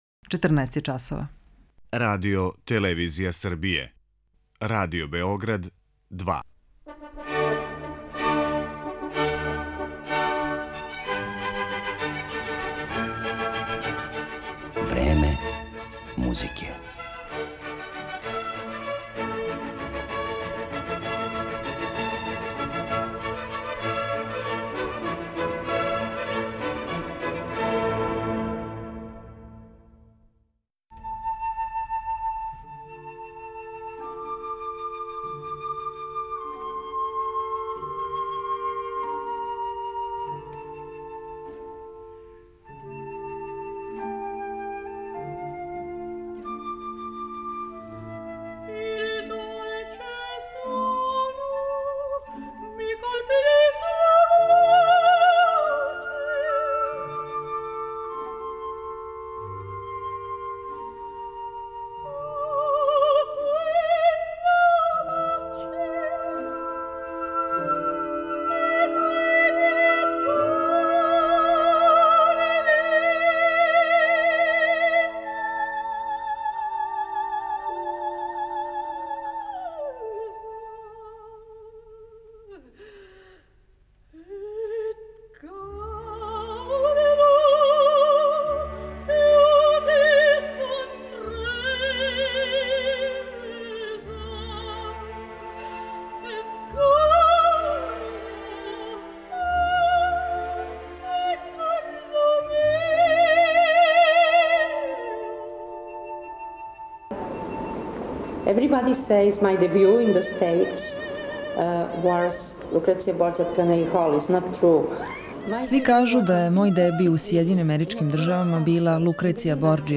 У емисији ће бити емитовани и одломци интервјуа снимљени са уметницом приликом њених гостовања код нас.